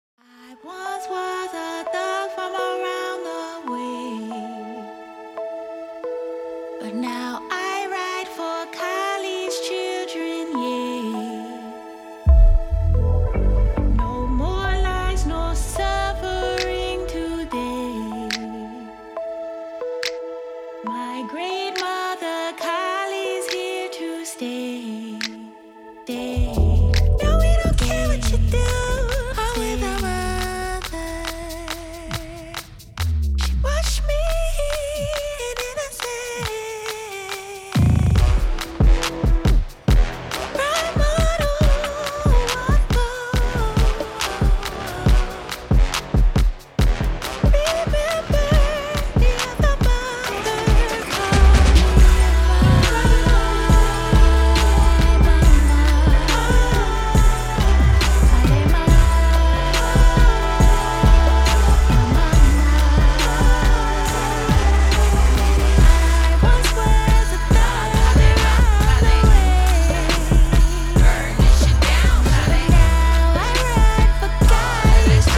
where post-modern soul meets dub lullabies and much more.
bursts with heavy drums